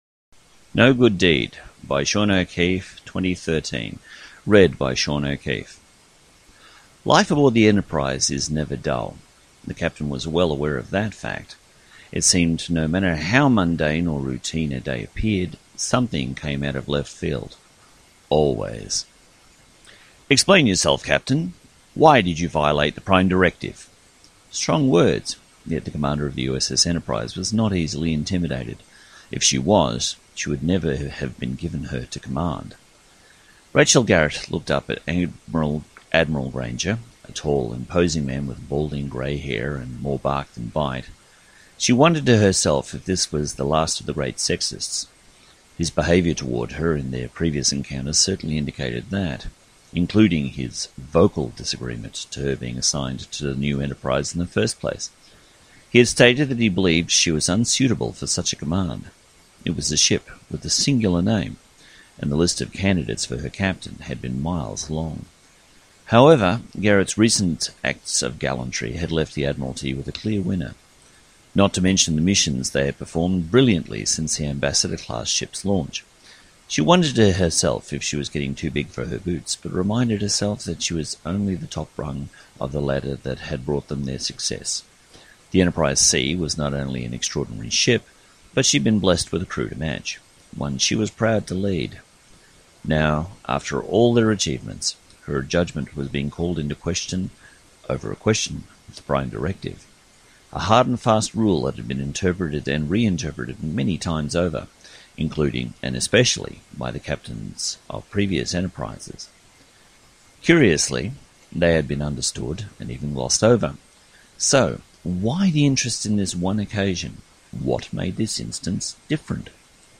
No-Good-Deed-Audiobook.mp3